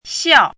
chinese-voice - 汉字语音库
xiao4.mp3